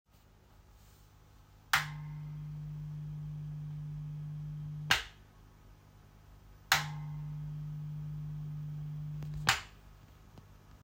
Hum in 'Operate'
However they all have a quiet hum out of the speaker on operate regardless of whether an amplifier is connected or not (you can hear it in the attached sound file I just recorded with my iPhone in front of the speaker).